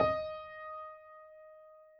Added more instrument wavs
piano_063.wav